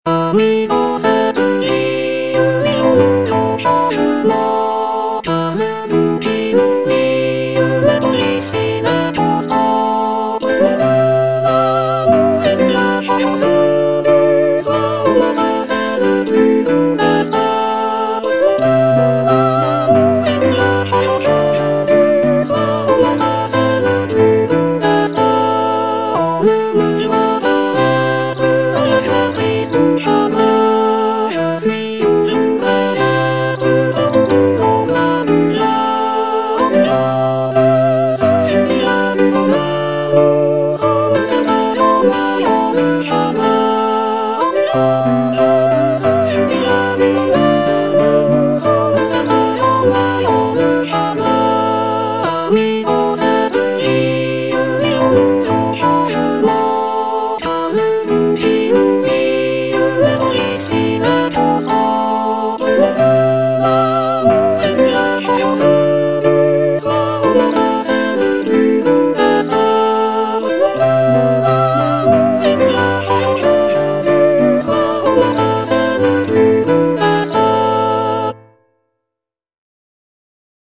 Soprano (Emphasised voice and other voices)
traditional French folk song